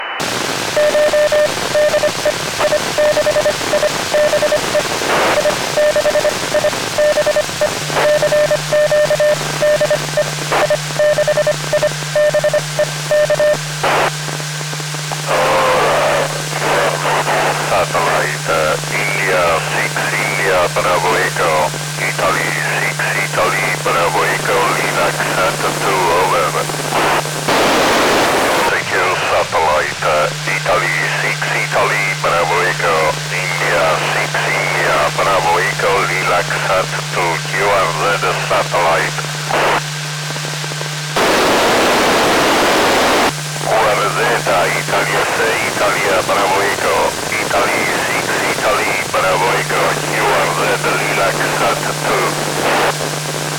LilacSAT-2 FM